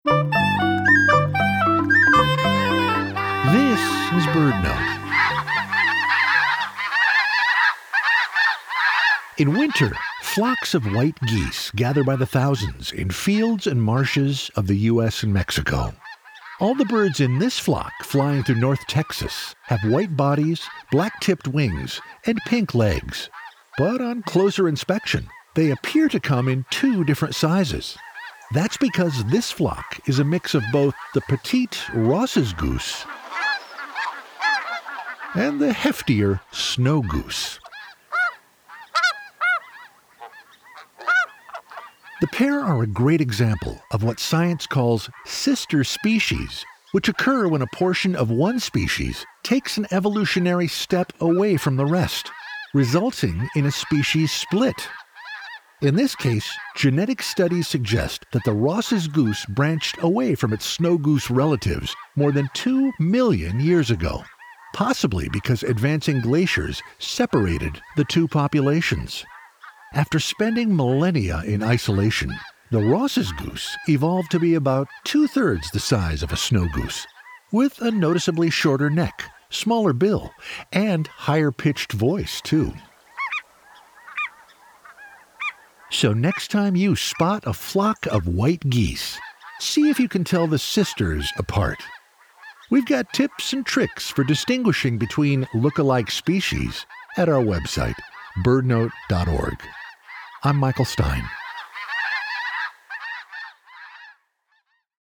After spending millennia in isolation, the Ross’s Goose evolved to be about ⅔ the size of a Snow Goose, with a noticeably shorter neck, smaller bill, and higher-pitched voice too.